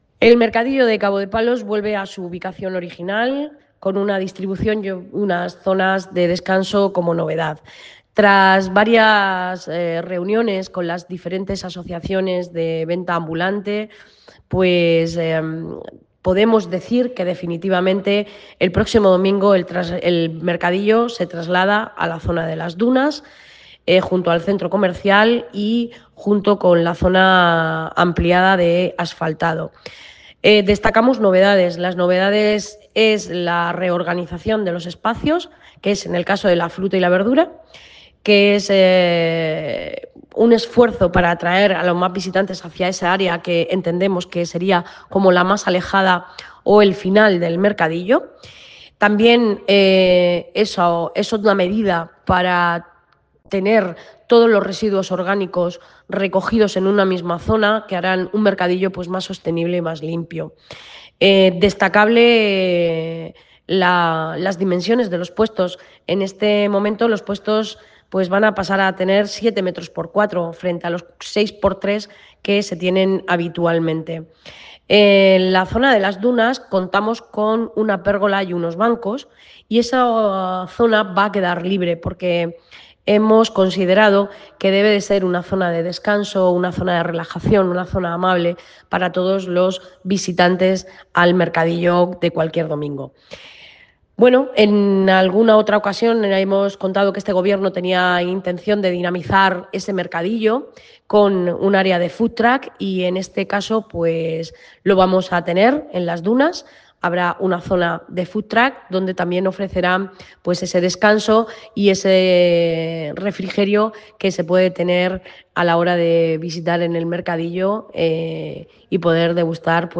Declaraciones de Belén Romero sobre el Mercadillo de Cabo de Palos